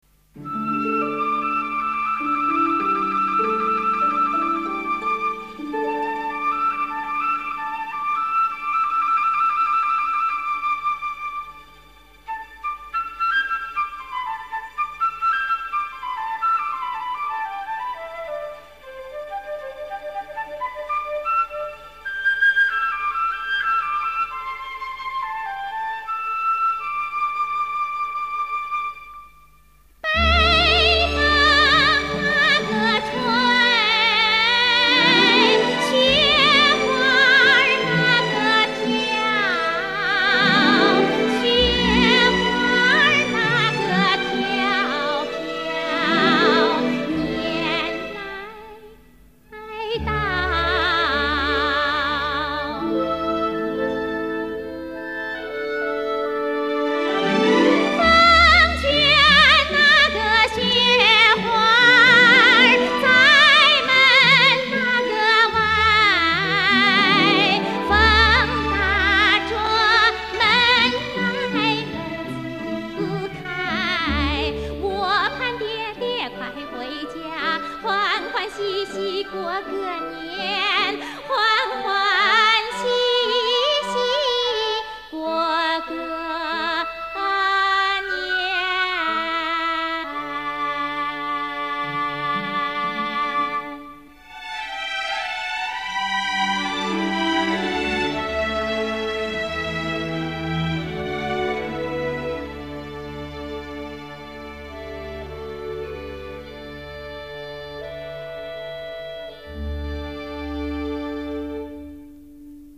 女声独唱